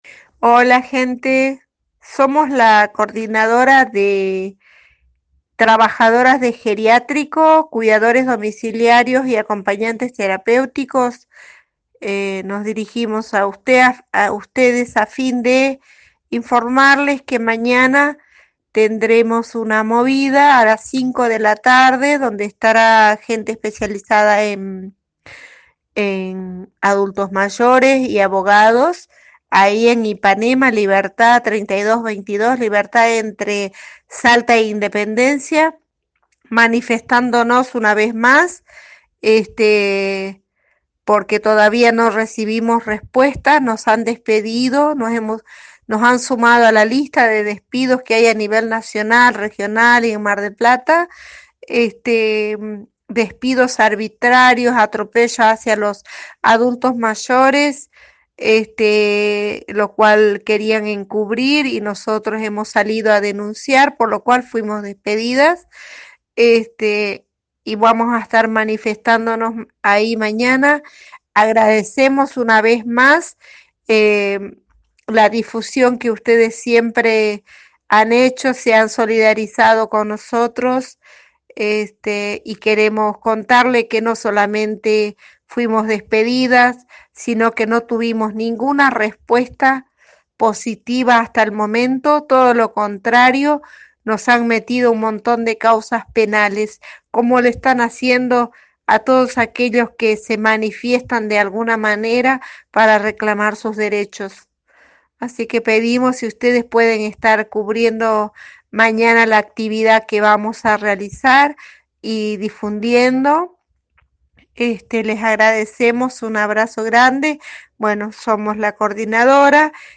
comentó al programa radial Bien Despiertos